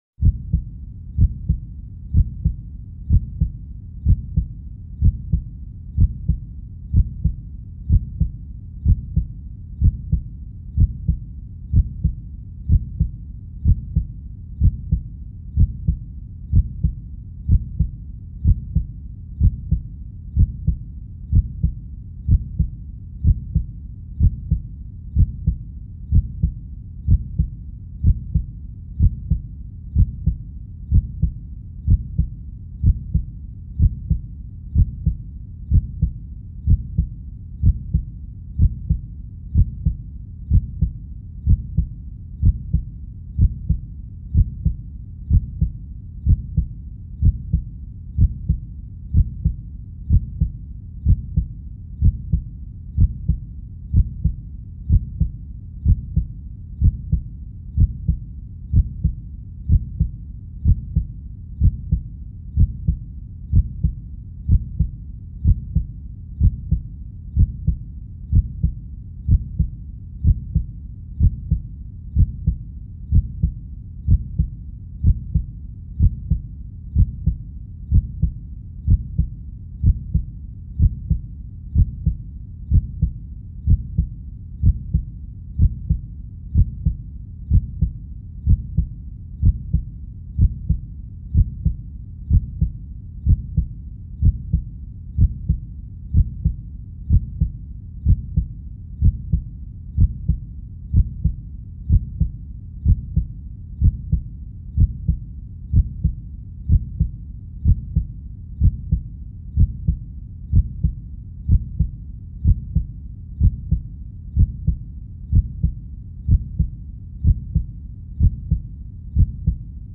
Звуки биения сердца
Вы можете скачать или слушать онлайн естественные ритмы: от размеренного стука для релаксации до учащенного пульса, создающего напряжение.
Шум ударов сердца